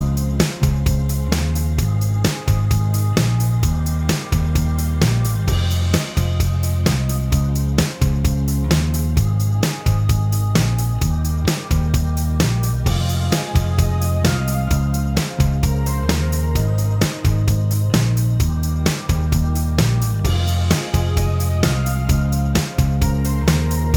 Minus All Guitars Pop (2000s) 4:08 Buy £1.50